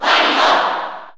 Category: Crowd cheers (SSBU) You cannot overwrite this file.
Wario_Cheer_Japanese_SSB4_SSBU.ogg